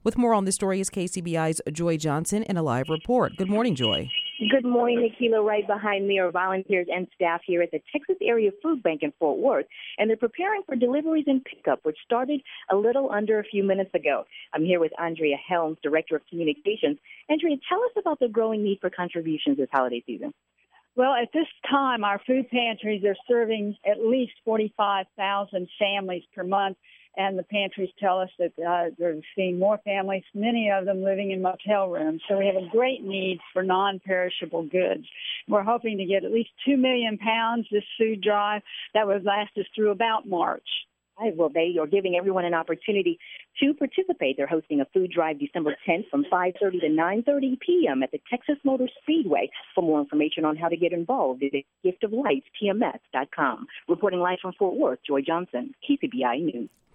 Their goal is to collect 2 million pounds of canned and boxed foods at the Texas Motor Speedway, Gift Of Lights on December 10th. Live Coverage